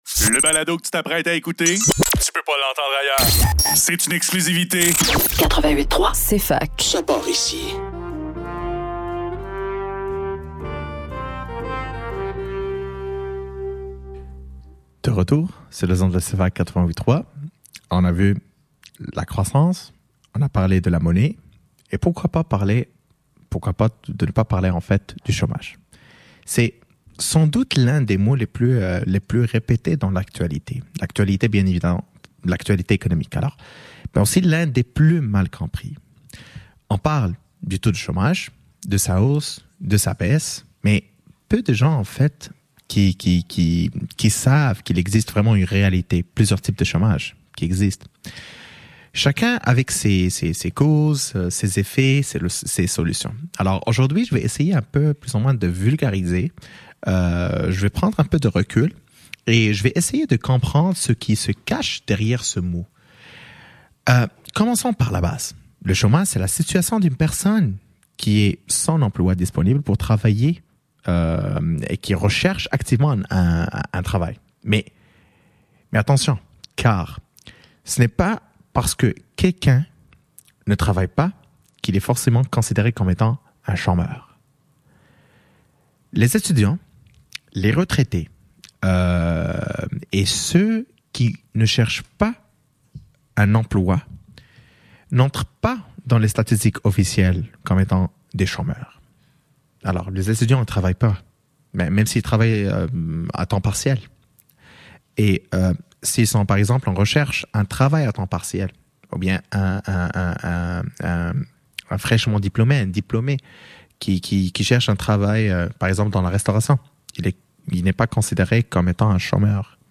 L'économie en mouvement – Chômage et inflation : comprendre les deux battements du cœur de l’économie – émission spéciale du Radiothon, 6 Novembre 2025